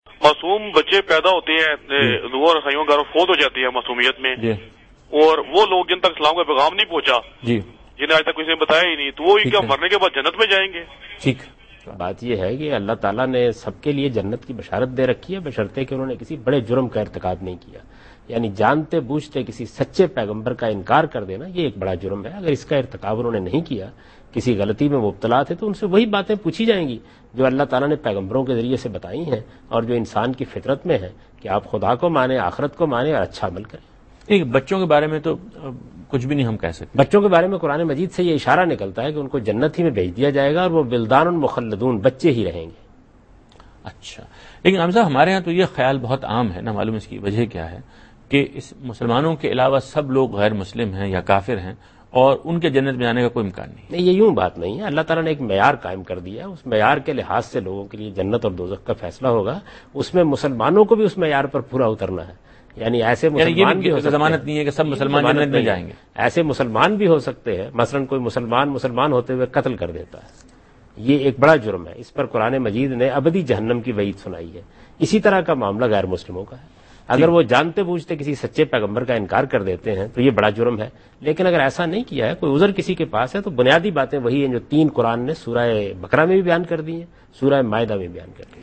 Category: TV Programs / Dunya News / Deen-o-Daanish /
Javed Ahmad Ghamidi answers a question regarding "Paradise for Non-Muslims" in program Deen o Daanish on Dunya News.